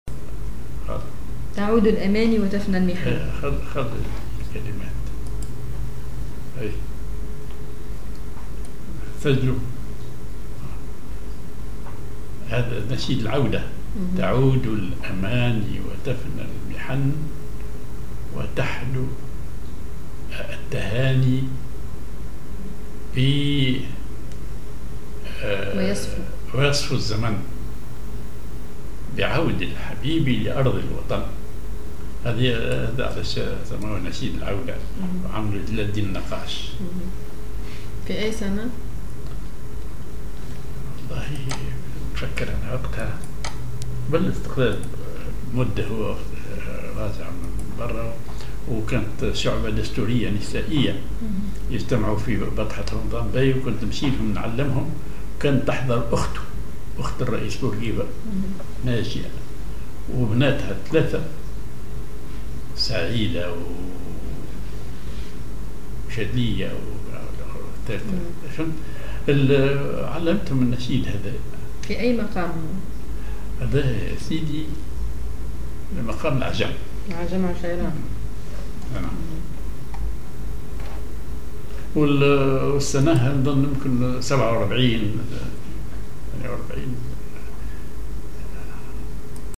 ar عجم عشيران